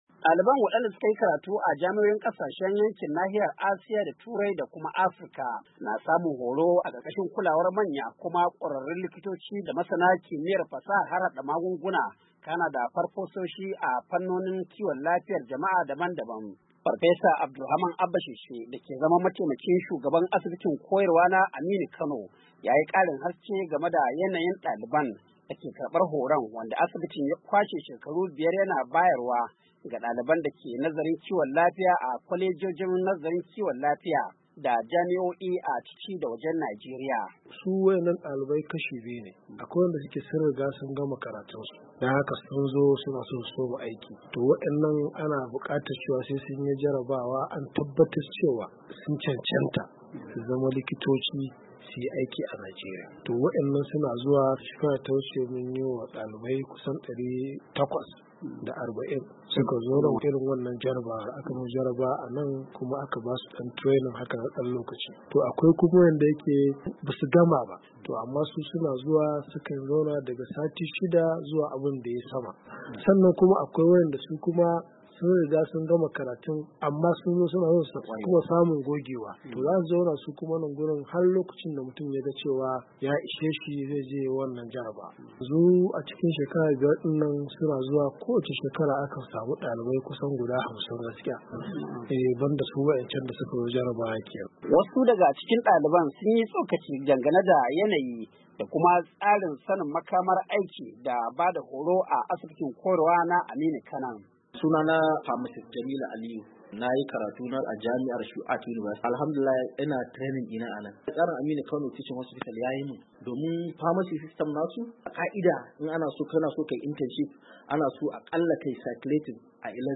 Wasu daga cikin daliban sun yi tsokaci dangane da yanayin tsarin sanain makamar aiki da bada horo a asibitin koyarwar na Aminu Kano.
Daga Kano ga rahotan